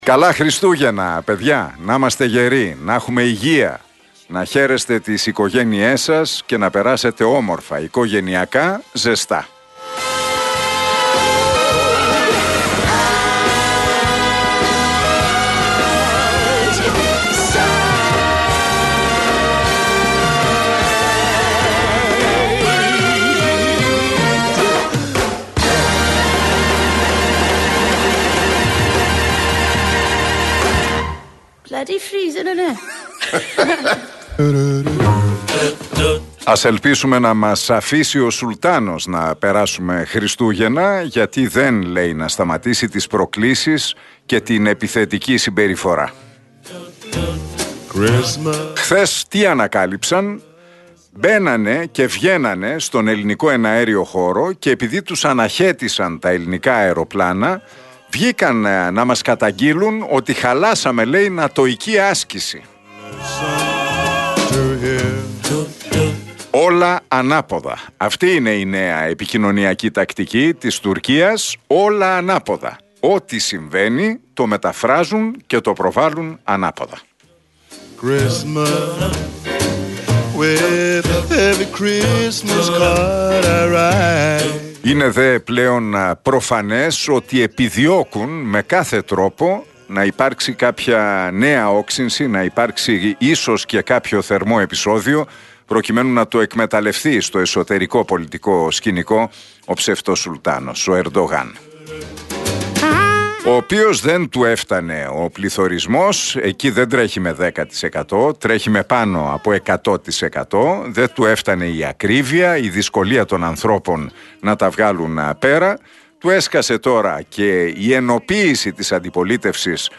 Ακούστε το σχόλιο του Νίκου Χατζηνικολάου στον RealFm 97,8, την Τετάρτη 21 Δεκεμβρίου 2022.